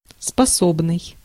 Ääntäminen
US : IPA : /ˈklɛ.vɚ/